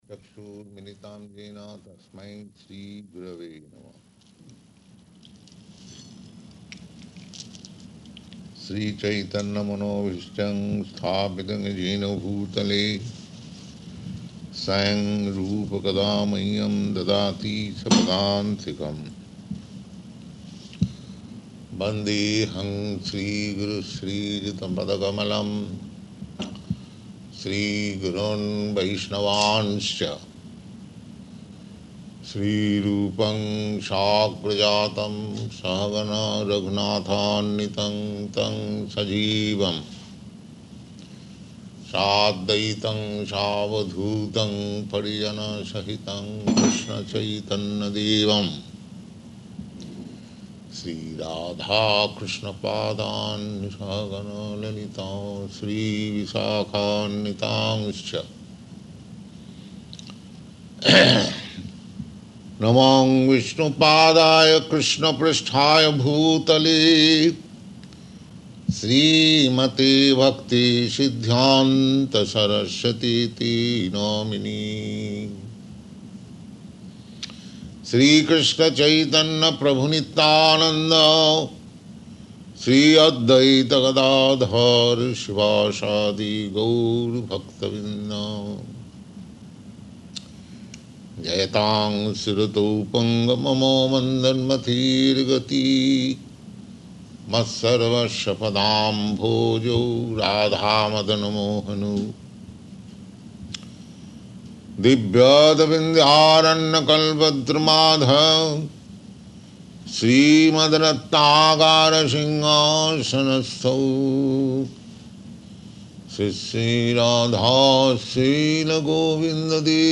Location: Hawaii
Prabhupāda: [chants maṅgalācaraṇa: ]